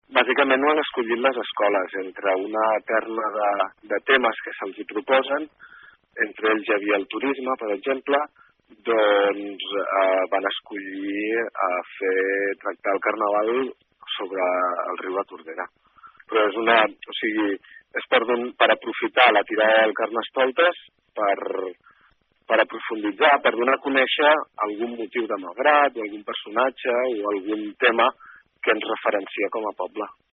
En declaracions a aquesta emissora, el regidor de Cultura de l’Ajuntament de Malgrat, Paco Márquez, ha explicat que aquesta és la temàtica escollida pels alumnes de les escoles del municipi d’entre diversos temes, que serveixen per donar a conèixer o promocionar algun aspecte o personatge destacat de la vila.